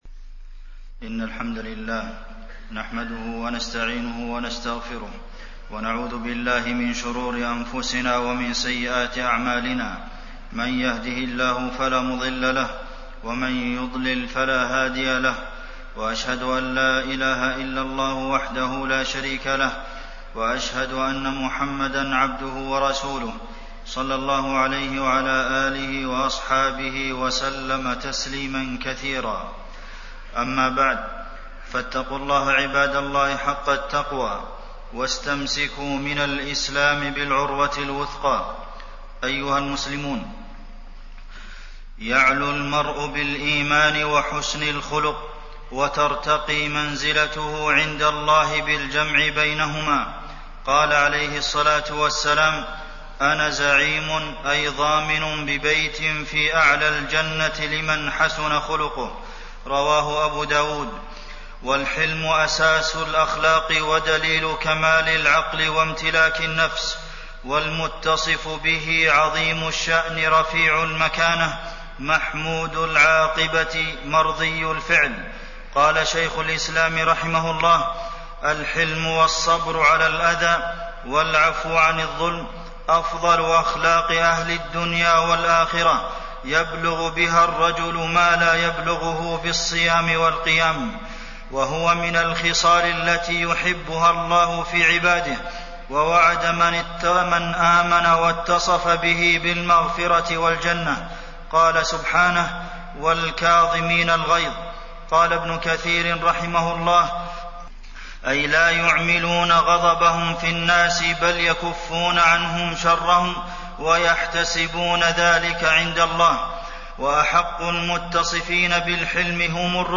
تاريخ النشر ٢٤ صفر ١٤٣٢ هـ المكان: المسجد النبوي الشيخ: فضيلة الشيخ د. عبدالمحسن بن محمد القاسم فضيلة الشيخ د. عبدالمحسن بن محمد القاسم فضل الحلم وتحاشي الغضب